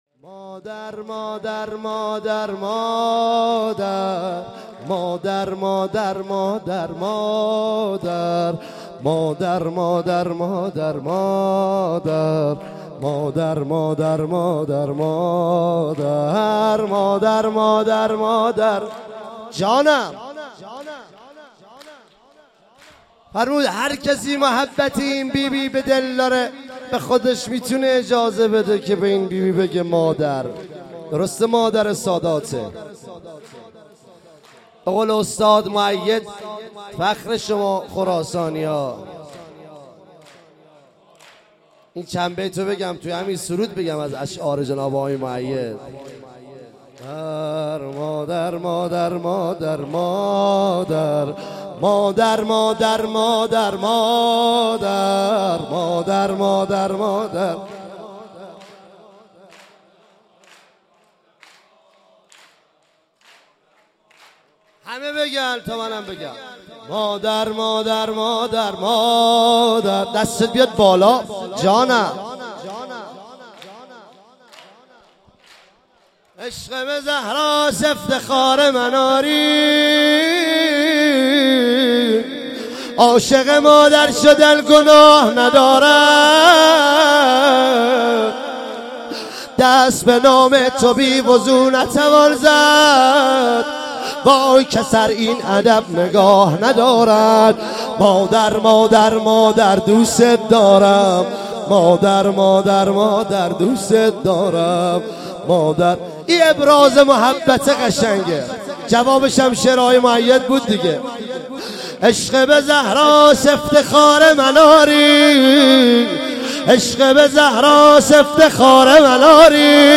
عنوان ولادت حضرت زهرا ۱۳۹۹ – شاندیز مشهد
سرود